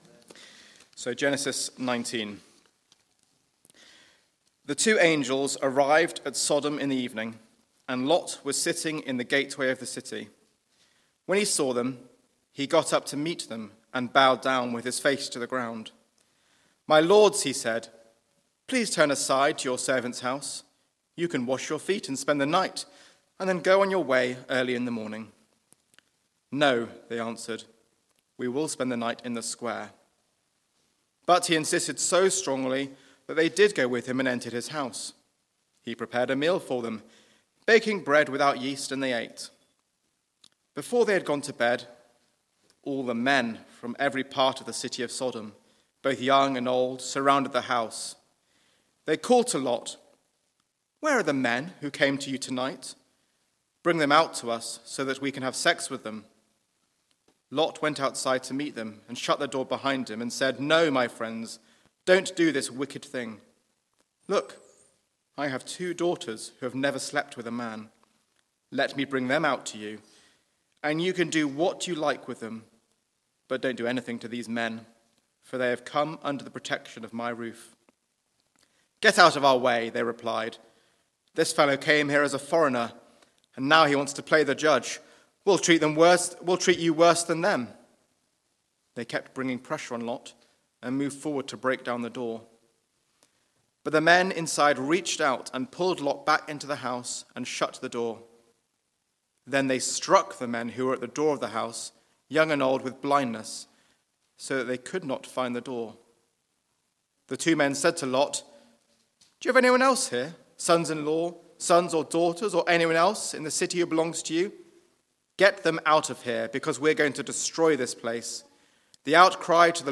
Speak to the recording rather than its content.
Media for Church at the Green Sunday 4pm